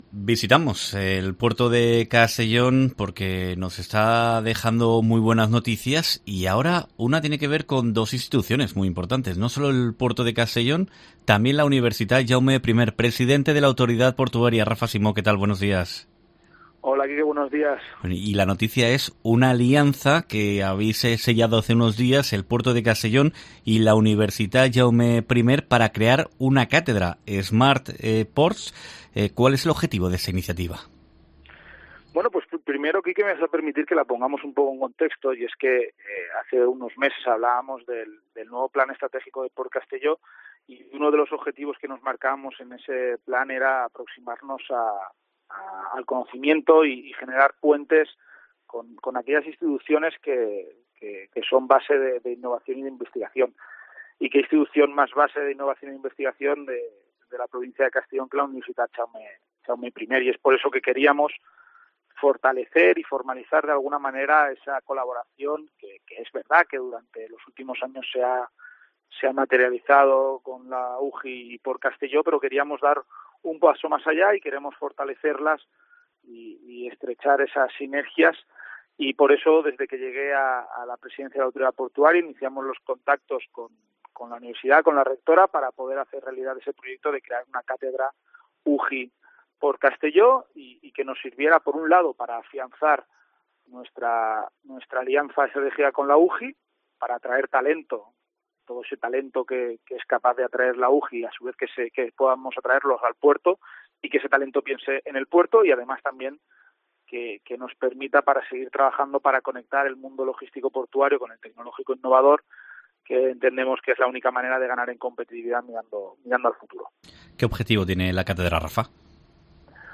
Entrevista
PortCastelló y la UJI crean una alianza a través de una cátedra como explica en COPE el presidente de la Autoridad Portuaria, Rafa Simó